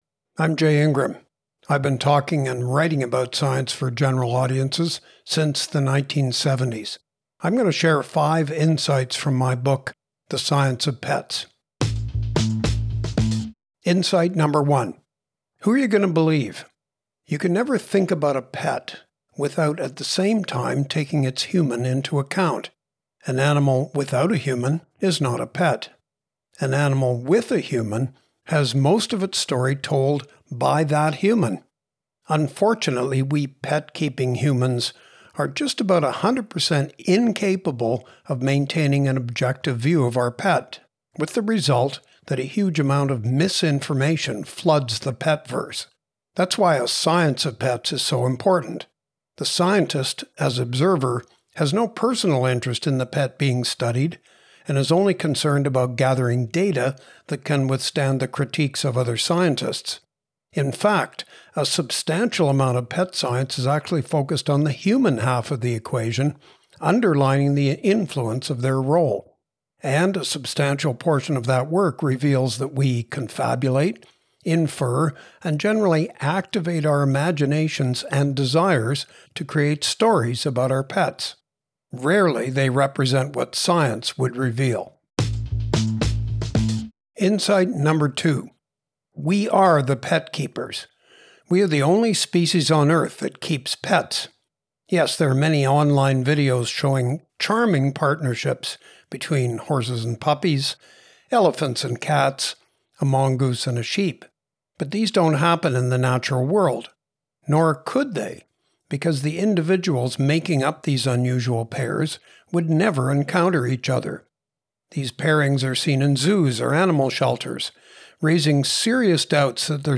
Listen to the audio version of this Book Bite—read by Jay himself—below, or in the Next Big Idea App.